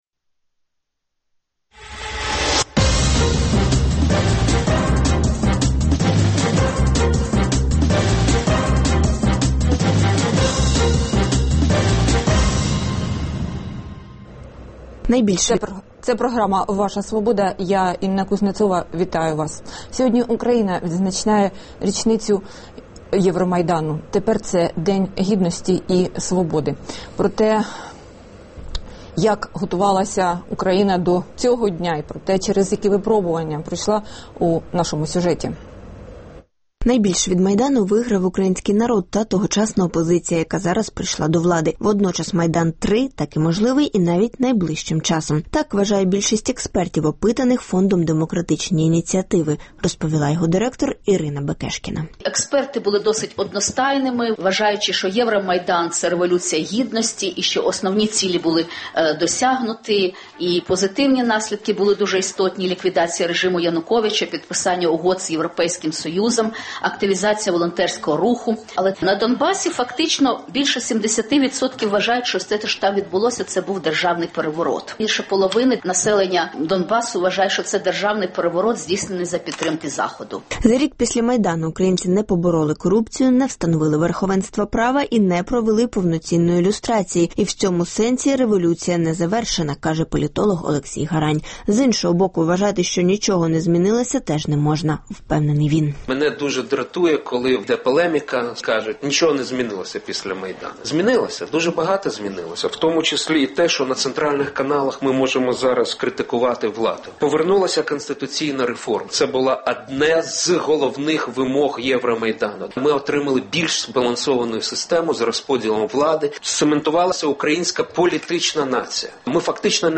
Гості: новообрані народні депутати України Тетяна Чорновіл та Ігор Луценко